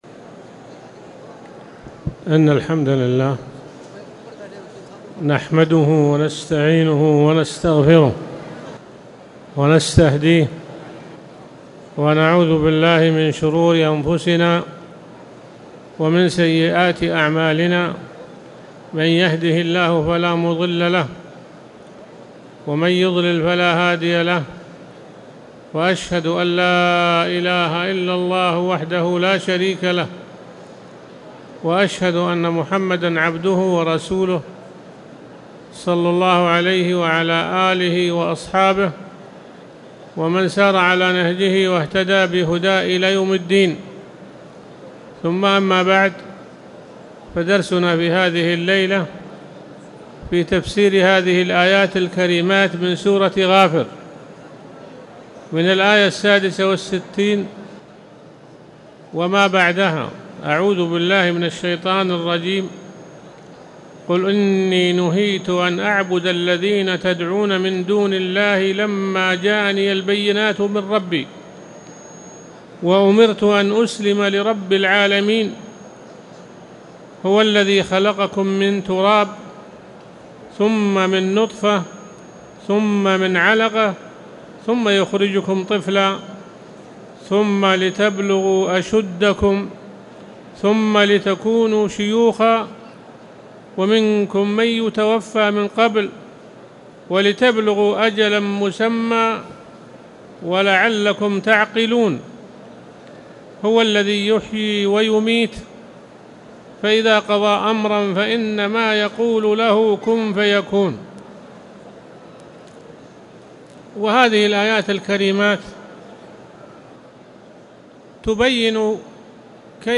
تاريخ النشر ٢٨ جمادى الآخرة ١٤٣٨ هـ المكان: المسجد الحرام الشيخ